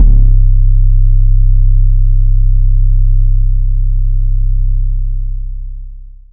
808 4.wav